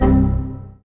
dong.mp3